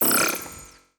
Hi Tech Alert 11.wav